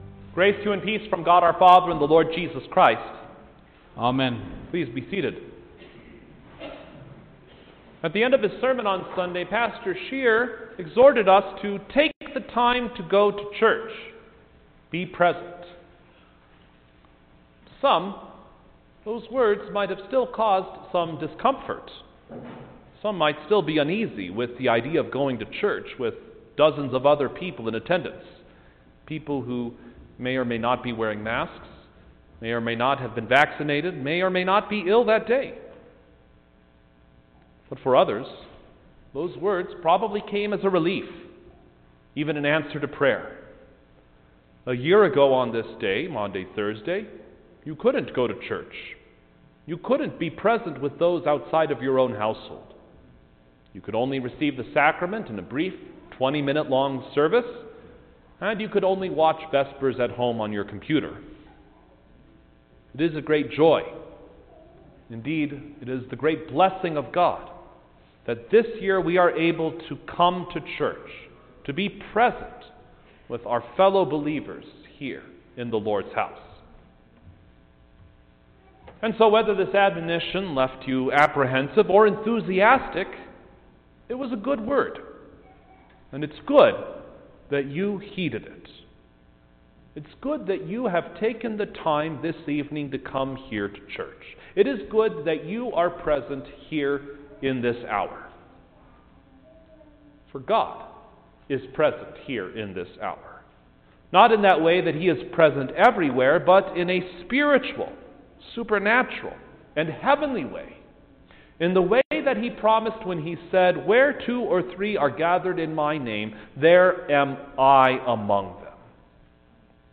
April-1_2021-Maundy-Thursday_Sermon-Stereo.mp3